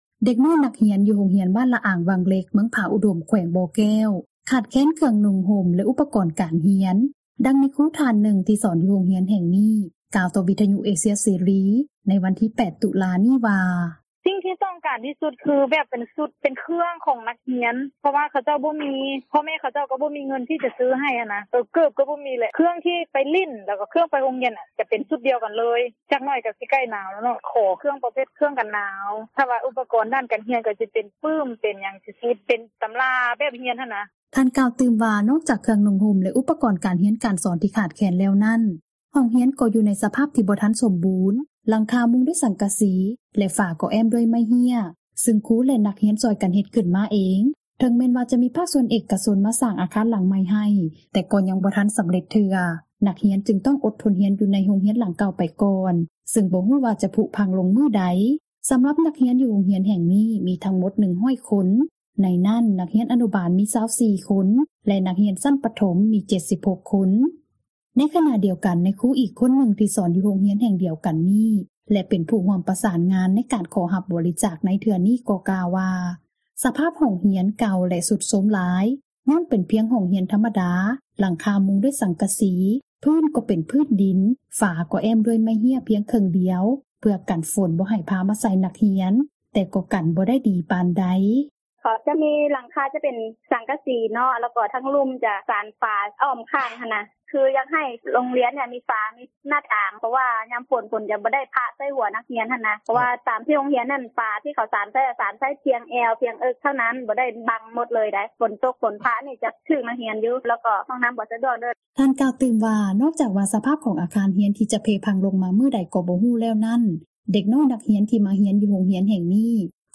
ນັກຂ່າວພົລເມືອງ
ເດັກນ້ອຍນັກຮຽນ ຢູ່ໂຮງຮຽນ ບ້ານລະອາງວັງເຫຼັກ ເມືອງຜາອຸດົມ ແຂວງບໍ່ແກ້ວ ຂາດແຄນເຄື່ອງນຸ່ງຫົ່ມ ແລະ ອຸປກອນການຮຽນ, ດັ່ງ ນາຍຄຣູທ່ານນຶ່ງ ທີ່ສອນຢູ່ໂຮງຮຽນແຫ່ງນີ້ ກ່າວຕໍ່ວິທຍຸເອເຊັຽເສຣີ ໃນວັນທີ 8 ຕຸລາ ນີ້ວ່າ: